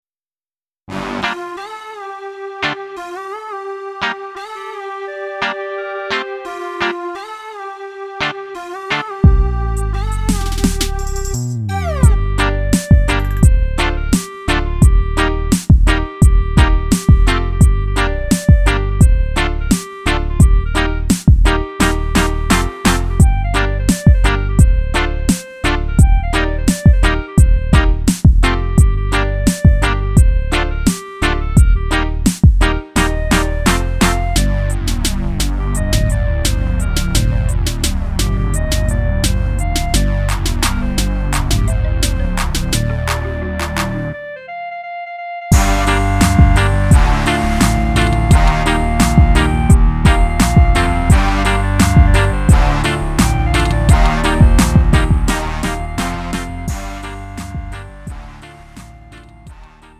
음정 -1키 3:11
장르 가요 구분